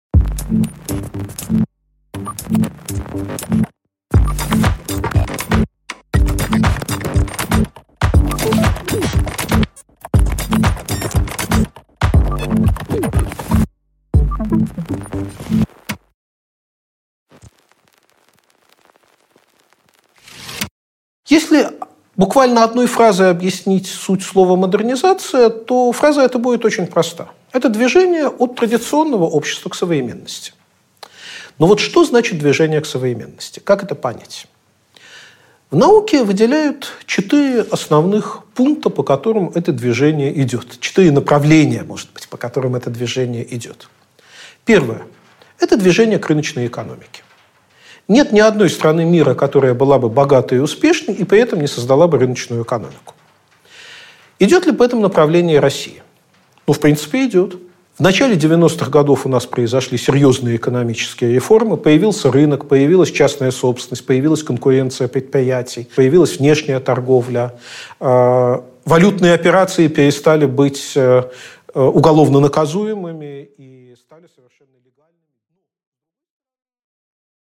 Аудиокнига Плавное движение | Библиотека аудиокниг